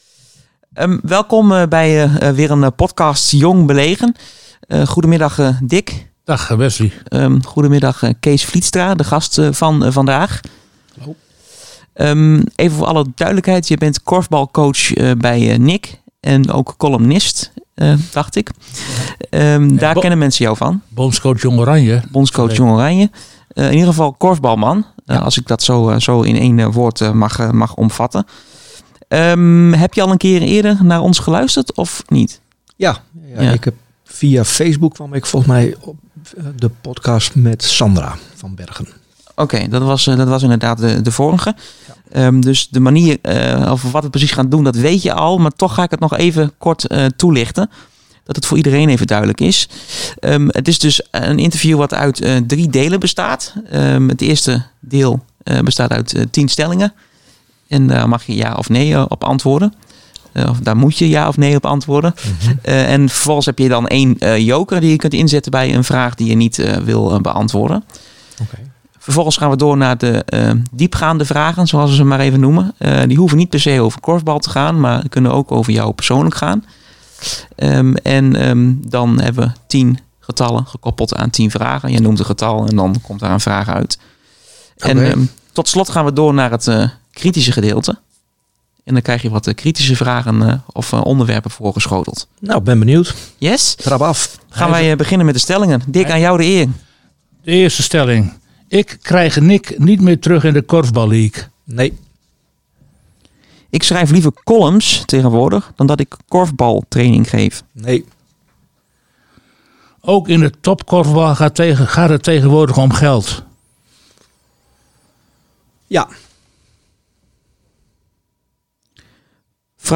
een persoonlijk en kritisch gesprek aangaan met bekende en minder bekende mensen uit de Groninger sportwereld.